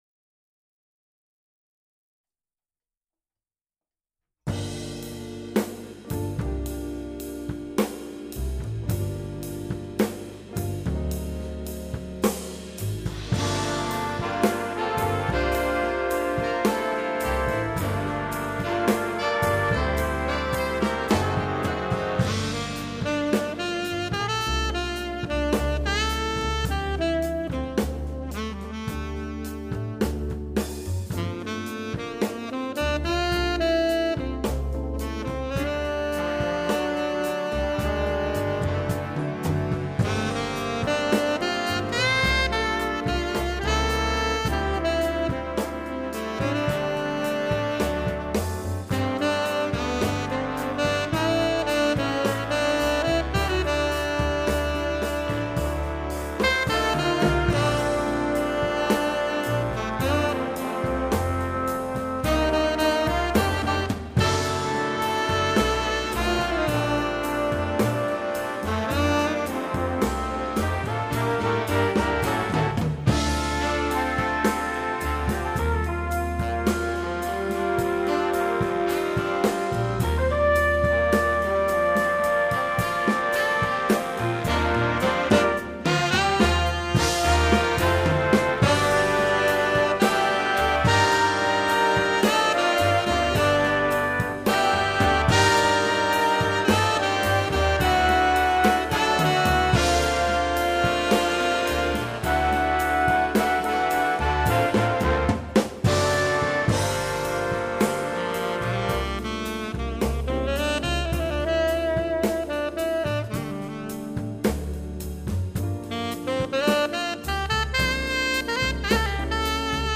Voicing: T Saxophone w/BB